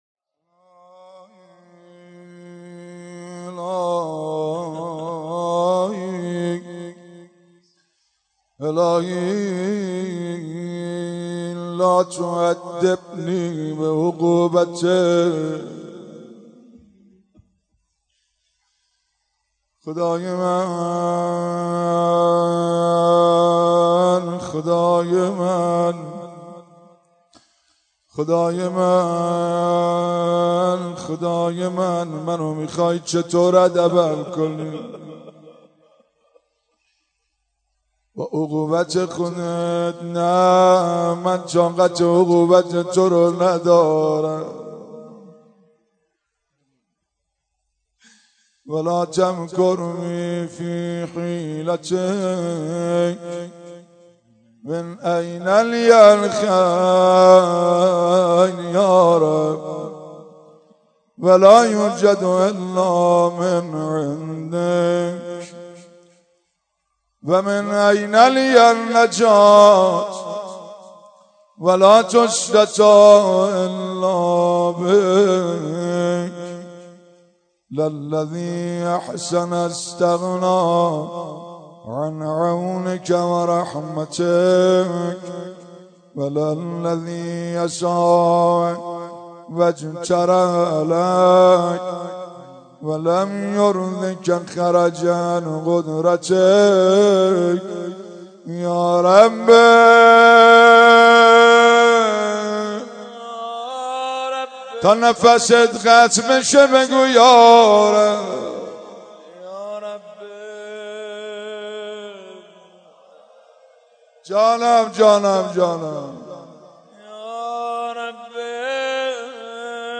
بخش اول-مناجات 1